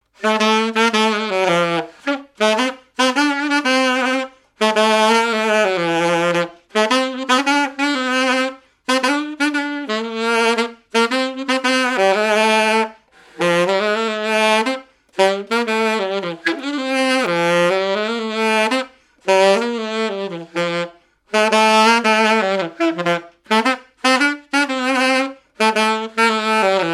activités et répertoire d'un musicien de noces et de bals
Pièce musicale inédite